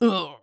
CK被击倒02.wav
CK被击倒02.wav 0:00.00 0:00.45 CK被击倒02.wav WAV · 38 KB · 單聲道 (1ch) 下载文件 本站所有音效均采用 CC0 授权 ，可免费用于商业与个人项目，无需署名。
人声采集素材/男2刺客型/CK被击倒02.wav